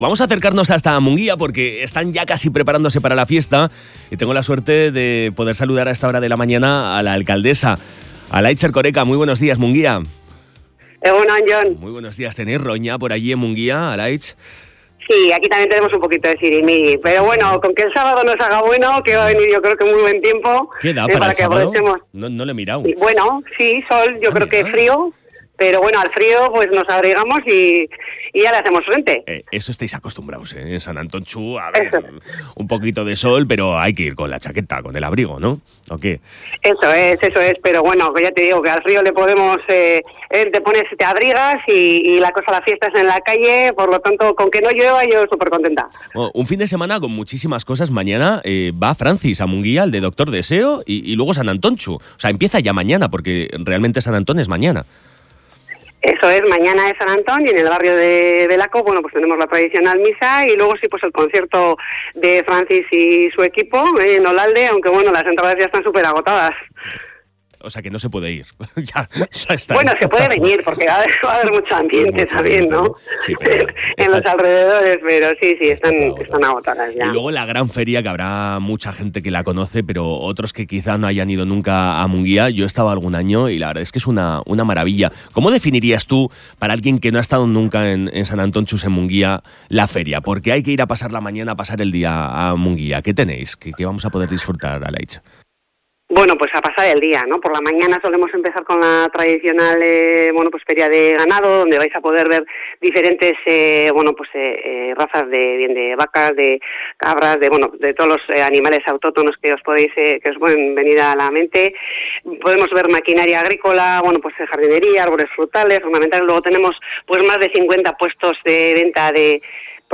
Mungia se prepara para celebrar las fiestas de San Antontxu y en El Madrugador hemos querido hablar con la alcaldesa, Alaitz Erkoreka, para que nos contase todo lo que podremos encontrar estos días en la localidad, donde ya sabemos que habrá buen tiempo.